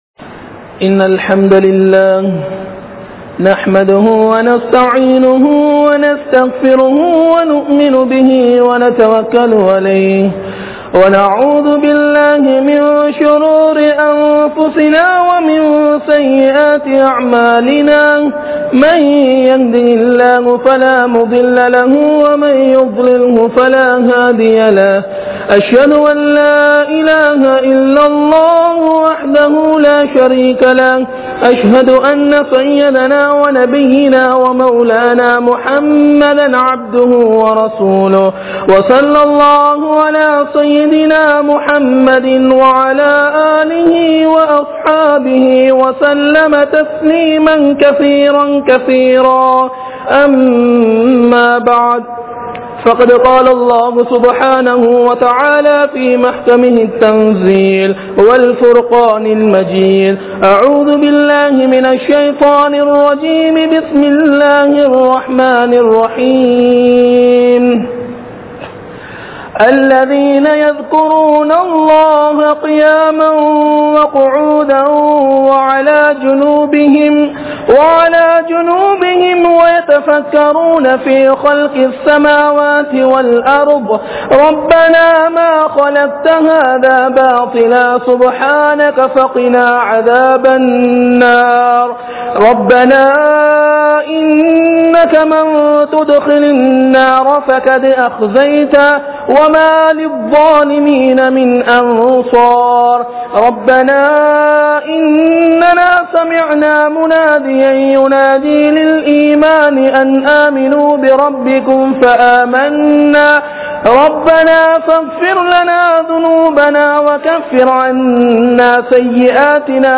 Qualities of a Muhmin (முஃமினின் பண்புகள்) | Audio Bayans | All Ceylon Muslim Youth Community | Addalaichenai
Aluthgama, Dharga Town, Meera Masjith(Therupalli)